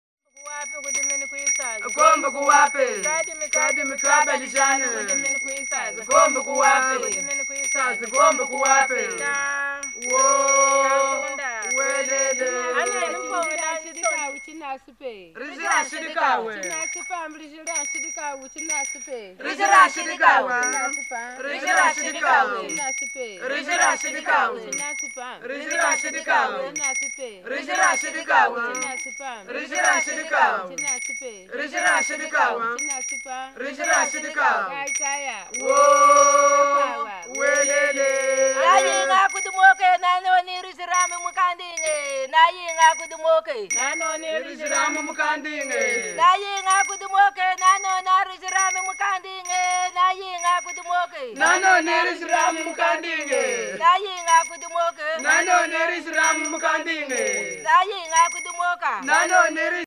Grelot et sonnaille
Lorsqu’on secoue l’instrument, la bille qui frappe le récipient fait tinter celui-ci.
Ce type d’instrument apparaît dans d’enregistrements de nos archives sonores, réalisés parmi les peuples congolais suivants ; il est connu sous les noms vernaculaires suivants: